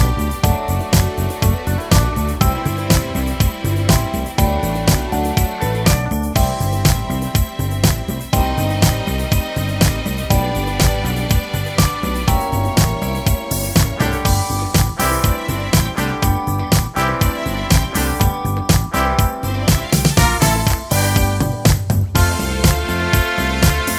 no Backing Vocals Soul / Motown 2:46 Buy £1.50